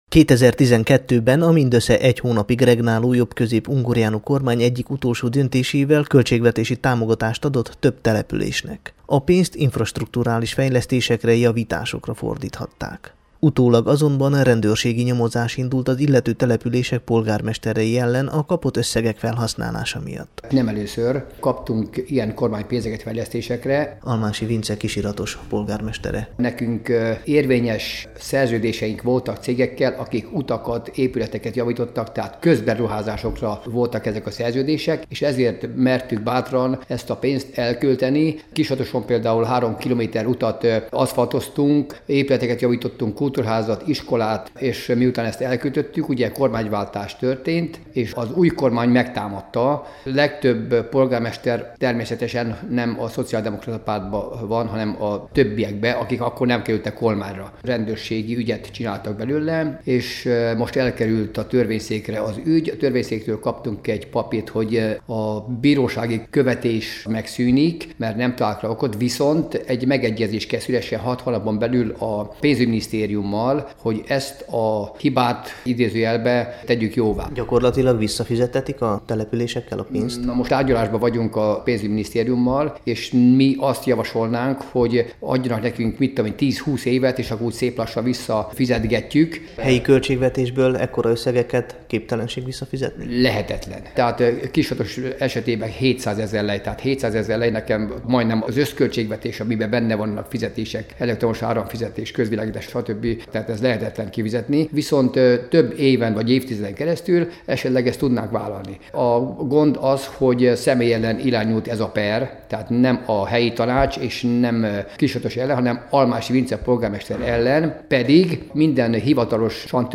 összeállítást a Temesvári Rádió számára az ügyről